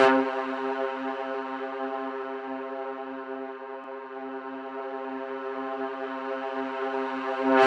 Stab